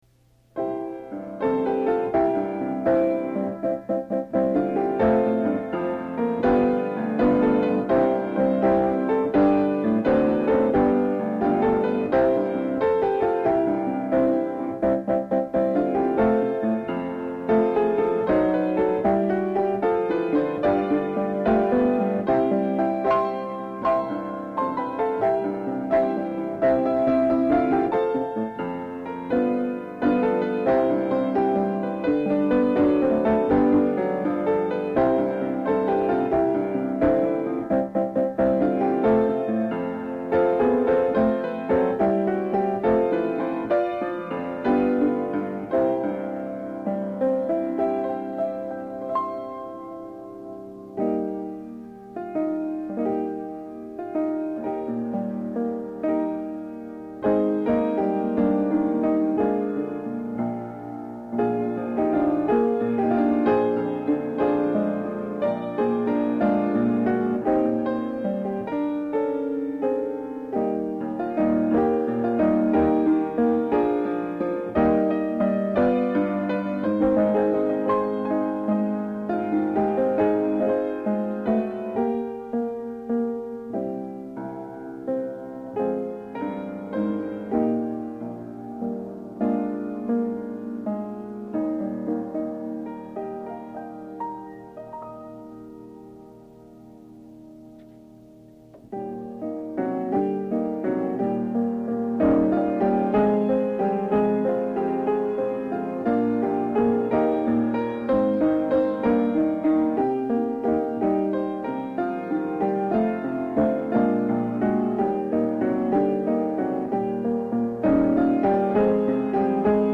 Hymns, Choruses and Songs for Worship (mp3 files)